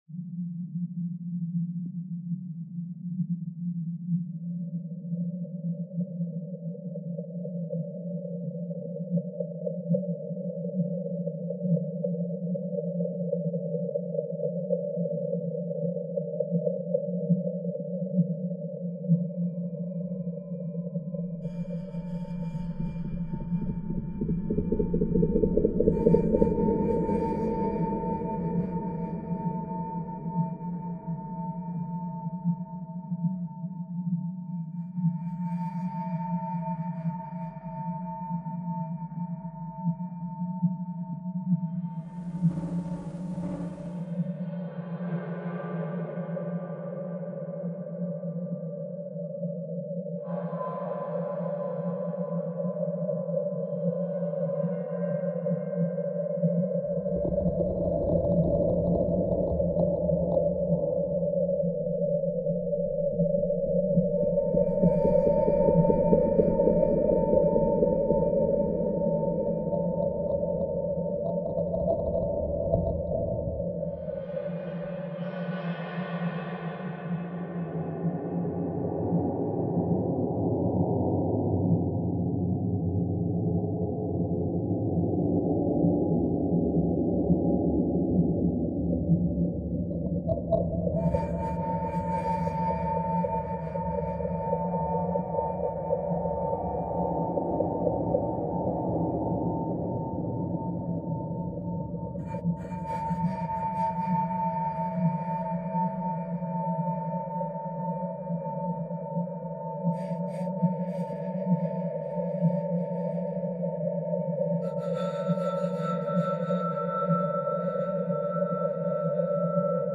Retrouvez une des ambiances sonores des lieux ! Pour un confort d'�coute optimal, il est fortement pr�conis� d'utiliser un casque audio :) Votre navigateur ne prend pas en charge l'audio HTML.